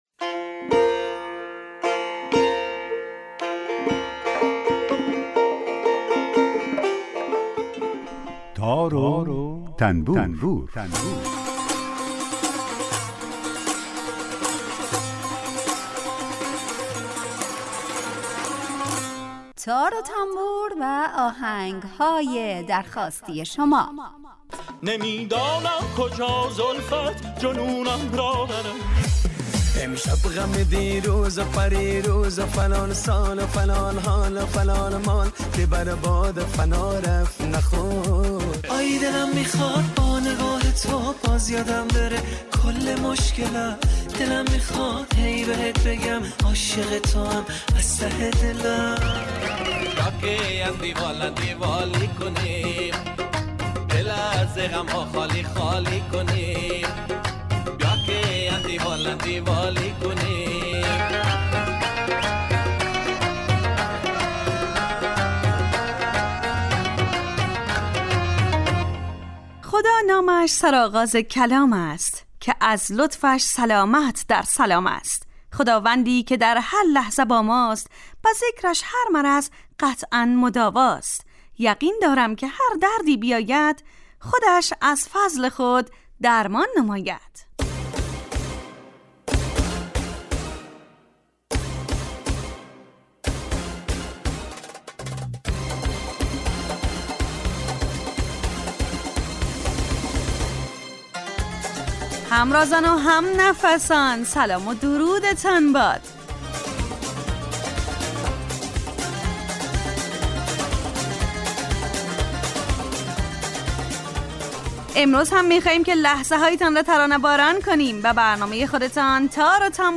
آهنگهای درخواستی